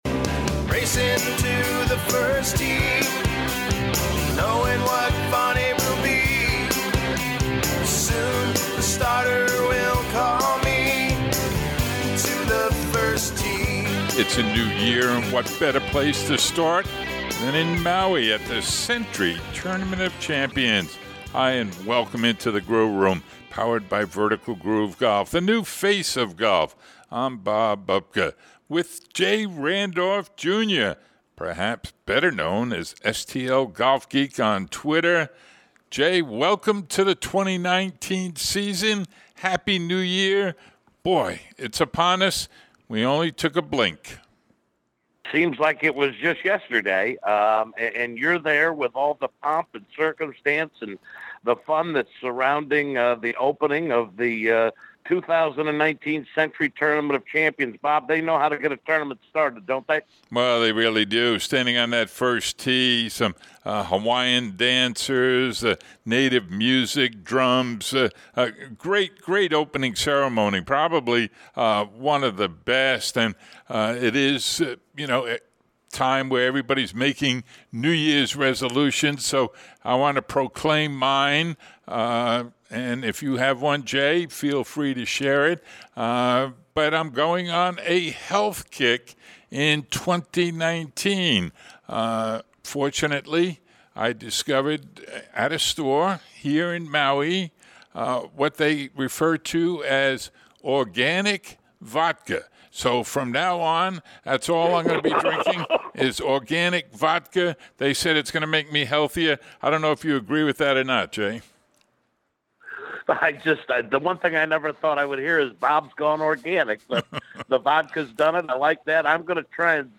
It's an action-packed show to kick off 2019 from the Sentry Tournament of Champions.